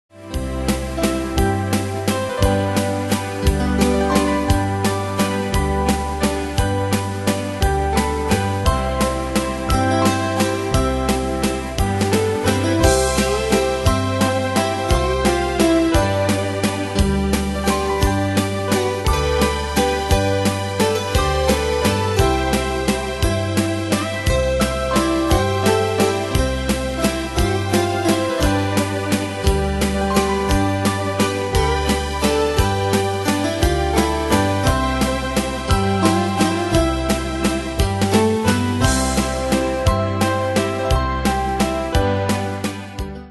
Style: Country Ane/Year: 1975 Tempo: 173 Durée/Time: 3.32
Danse/Dance: Valse/Waltz Cat Id.
Pro Backing Tracks